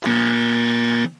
buzzer